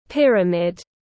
Hình chóp tiếng anh gọi là pyramid, phiên âm tiếng anh đọc là /’pirəmid/.
Pyramid /’pirəmid/